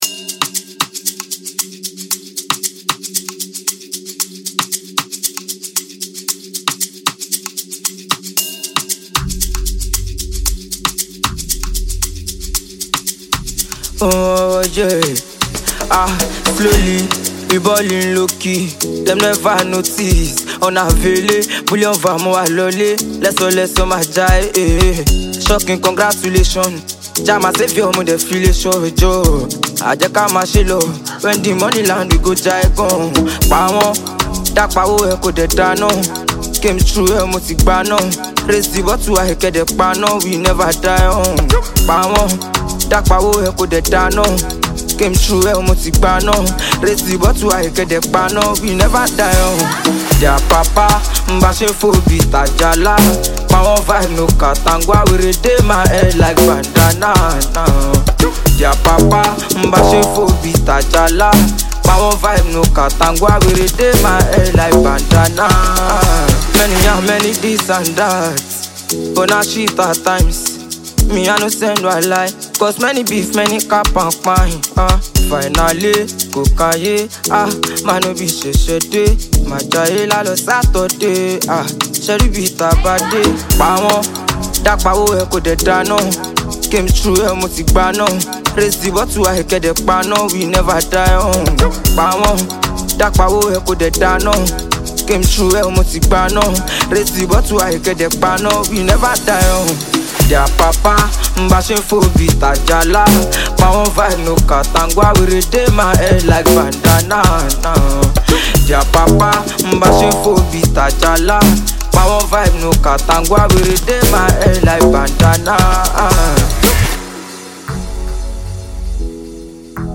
Remarkable Nigerian singer
a unique song with a breathtaking beat